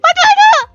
Worms speechbanks
Hurry.wav